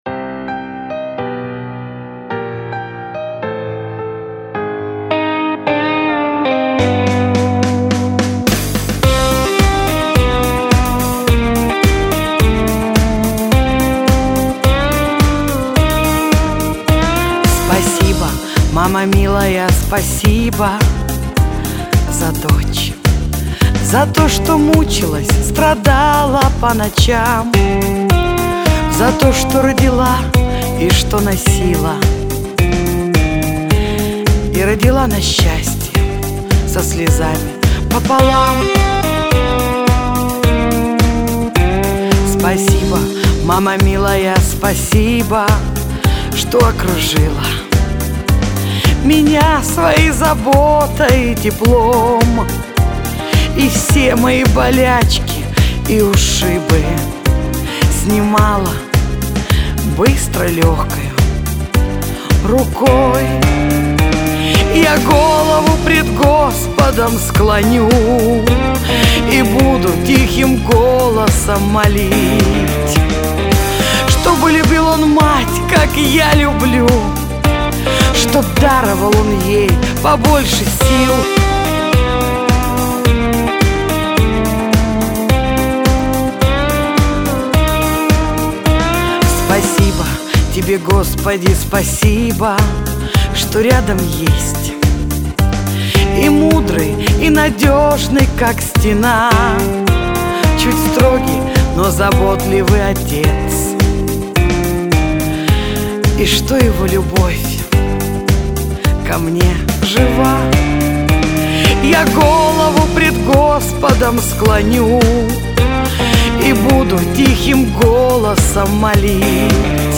Категория: Шансон